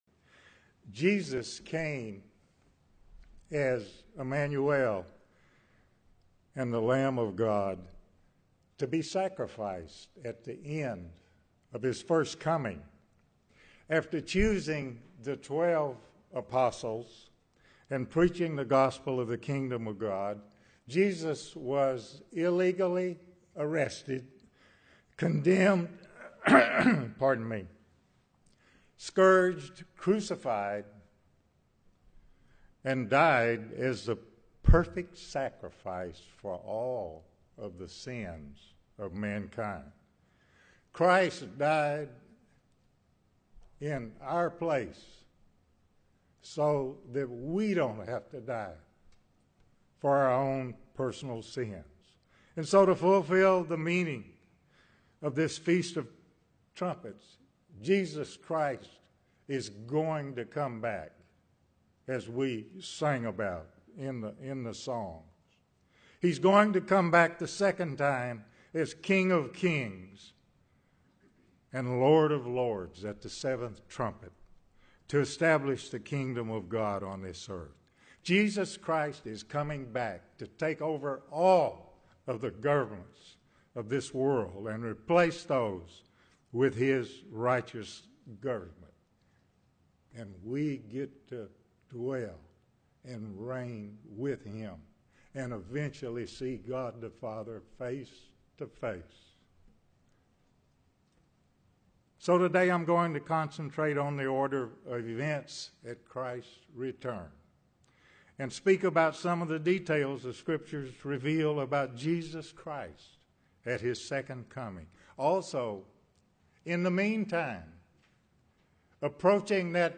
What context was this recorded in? In this Feast of Trumpets sermon, we will review the prophetic events leading up to and including the return of Jesus Christ to this earth in the establishment of the Kingdom of God.